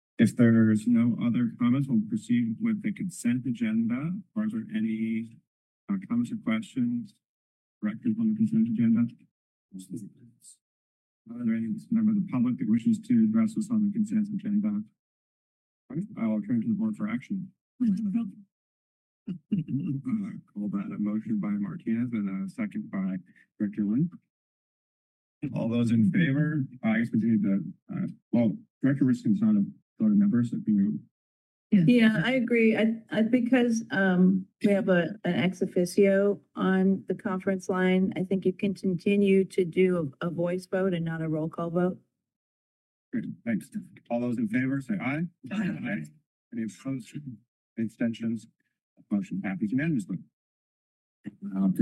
It is extracted directly from the video of the meetings.